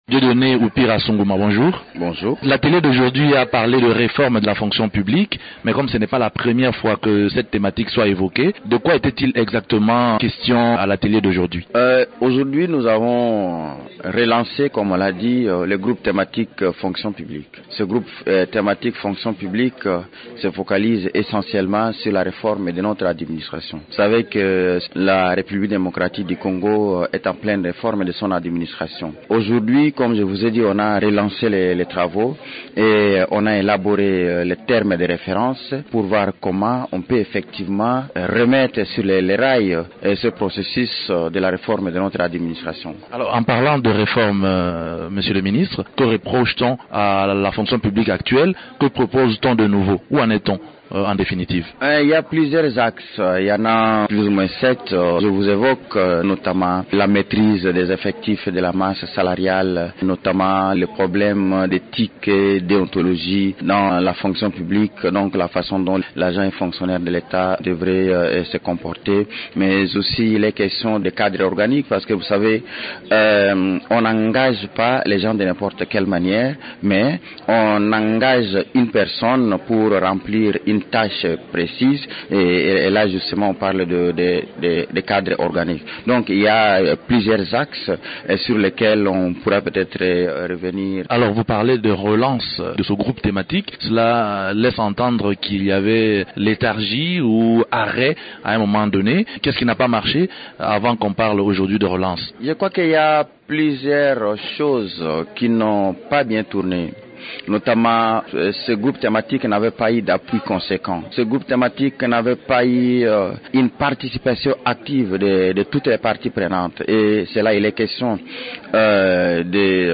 Le ministre la Fonction publique de la RDC explique l’avancement du processus de réforme de la Fonction publique en RDC.